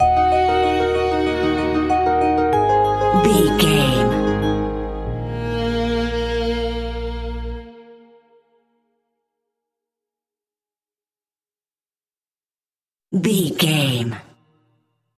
Emotional Electro Strings Stinger.
Aeolian/Minor
B♭
dreamy
ethereal
melancholy
hopeful
piano
cello
electronic